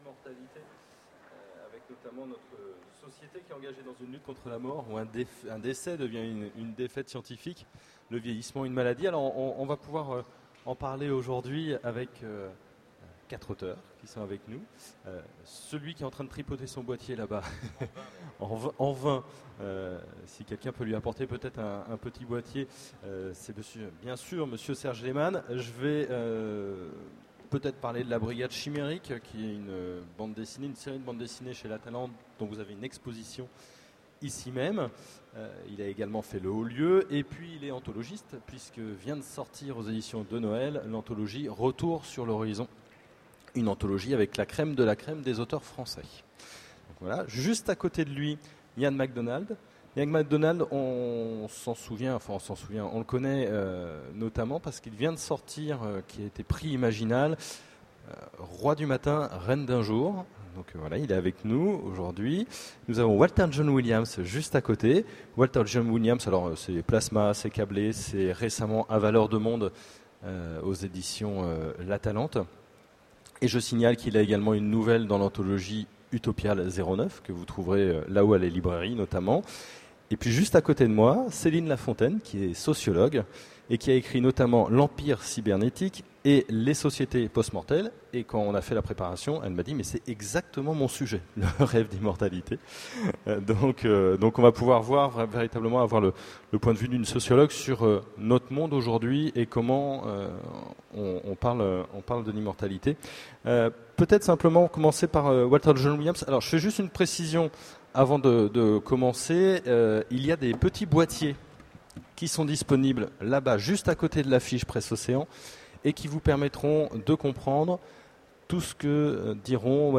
Voici l'enregistrement de la Conférence "Le Rêve d'Immotalité" aux Utopiales 2009.